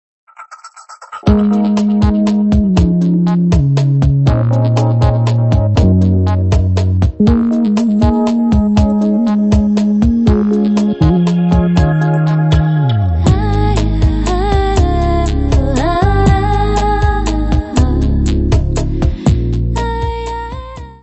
world ambient
voz
keyboards
bateria, percussão.
Área:  Pop / Rock